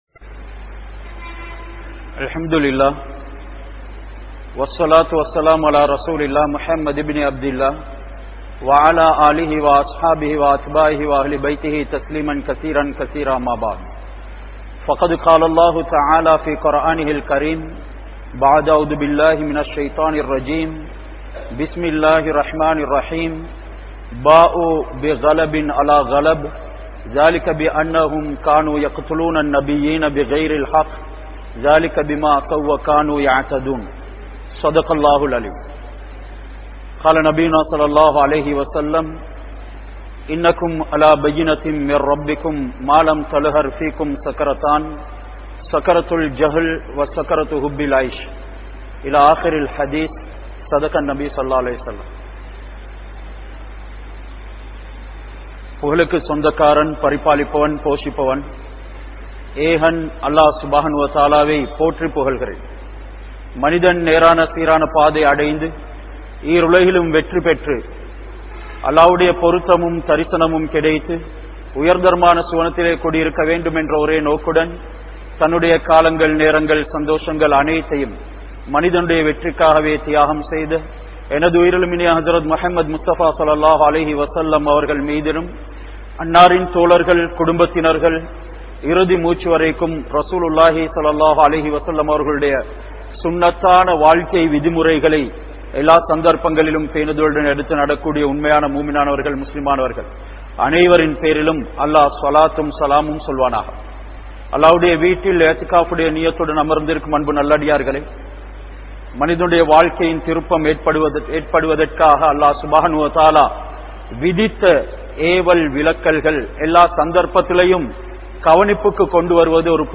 Palastine Makkal Ean Poaraaduhintranar? (பலஸ்தீன் மக்கள் ஏன் போராடுகின்றனர்?) | Audio Bayans | All Ceylon Muslim Youth Community | Addalaichenai
Kandy, Line Jumua Masjith